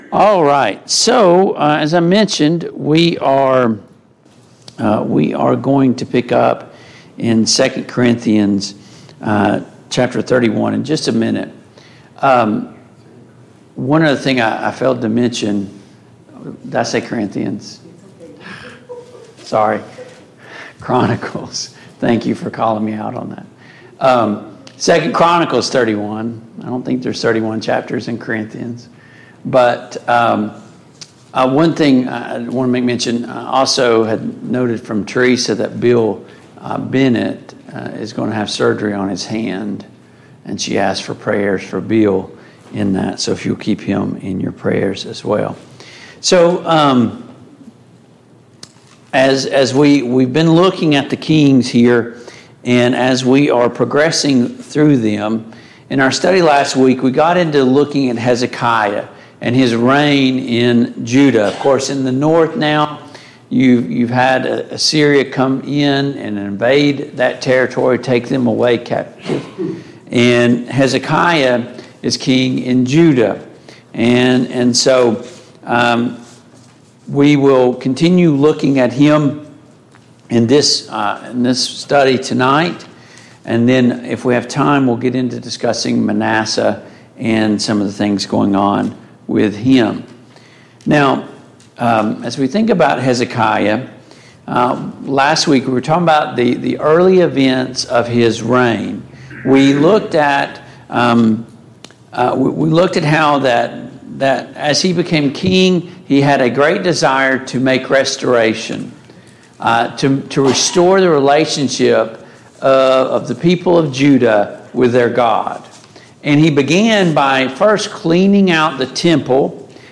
The Kings of Israel and Judah Passage: 2 Kings 18, 2 Chronicles 31, 2 Chronicles 32 Service Type: Mid-Week Bible Study Download Files Notes « 72.